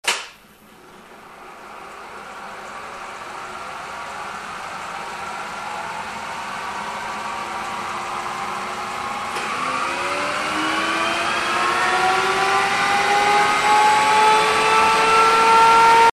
ruidos de roce han desaparecido.